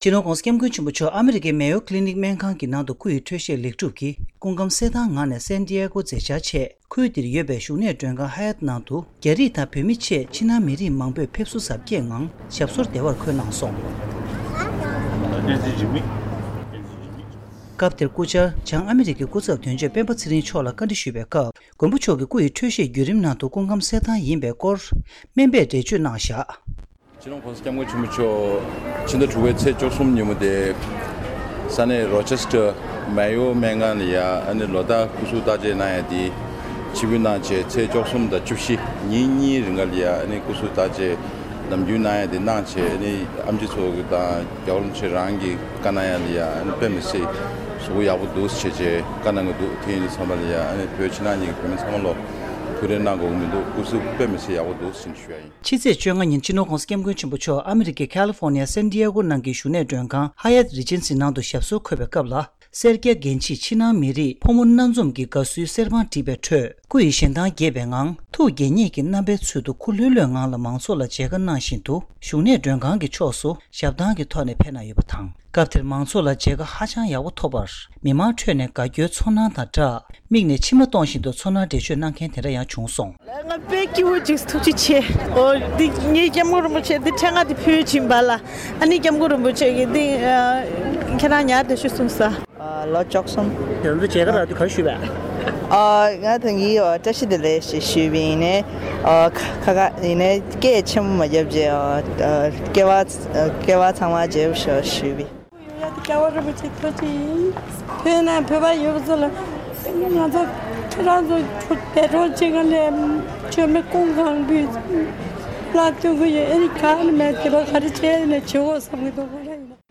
༸གོང་ས་མཆོག་སེན་ཌིཡེགྷོ་ས་གནས་སུ་ཞབས་སོར་འཁོད་སྐབས་ཀྱི་གནས་ཚུལ་ས་གནས་ནས་བཏང་བ།
སྒྲ་ལྡན་གསར་འགྱུར། སྒྲ་ཕབ་ལེན།